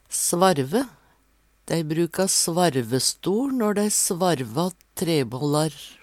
svarve - Numedalsmål (en-US)